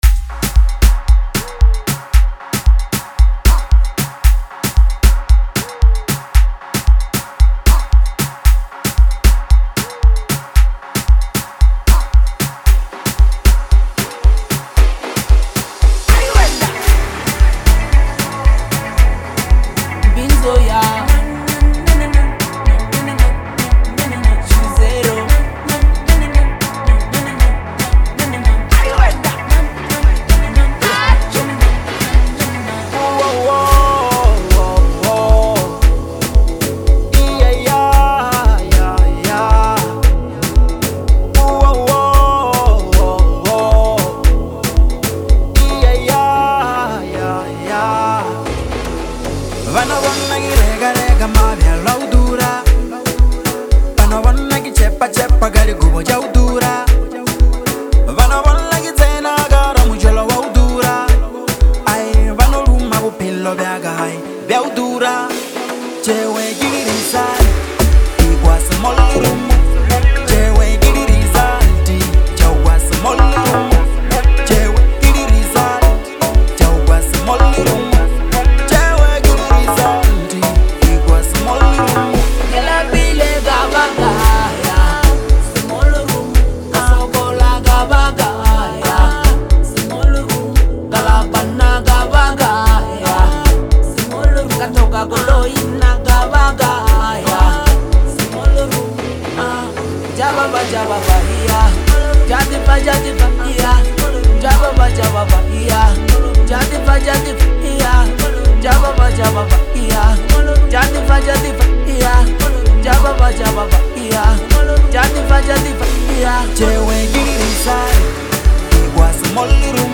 is a high energy track